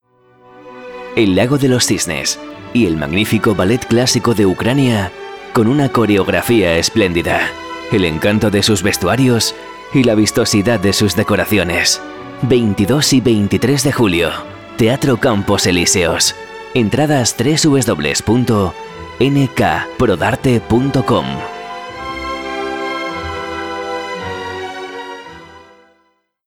Escucha la publicidad de El lago de los cisnes. Espectáculo de ballet clásico.
Escuchar más demos de producción en radio